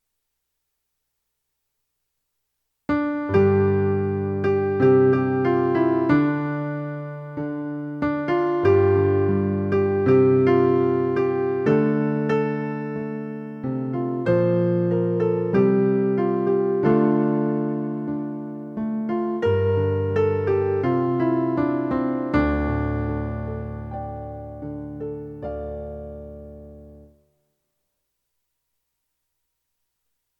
Create In Me - Cantor - Verse 2